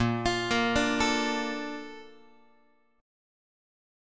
BbM7 chord